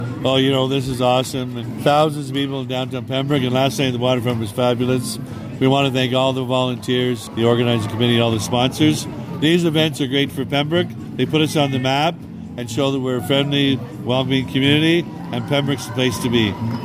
Deputy Mayor Brian Abdallah called it a proud day for the City: